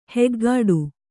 ♪ heggāḍu